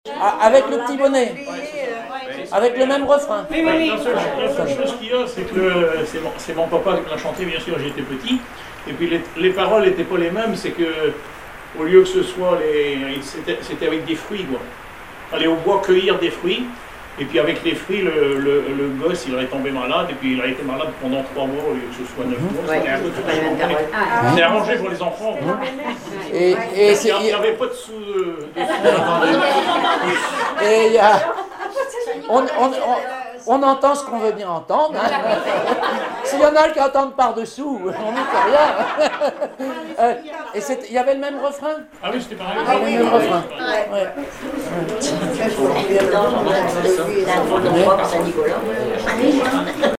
Informateur(s) Club d'anciens de Saint-Pierre association
Catégorie Témoignage